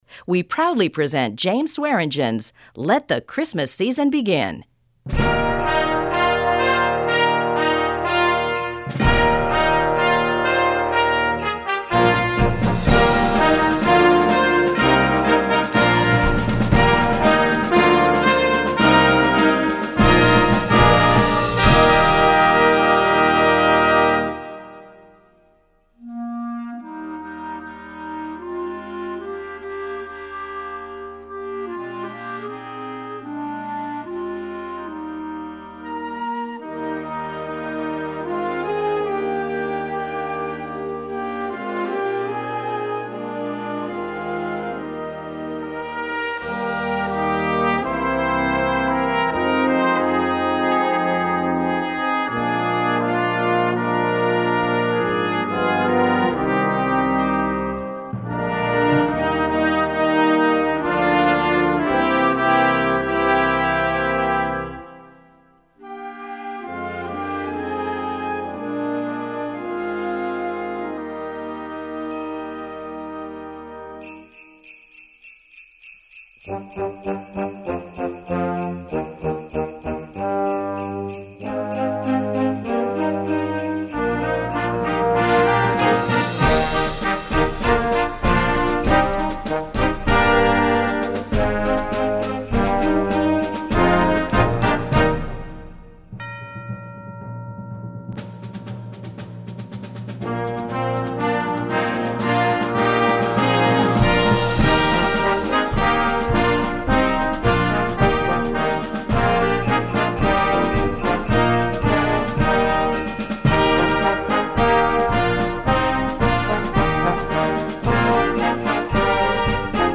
Gattung: Young Band
Besetzung: Blasorchester
A showstopper!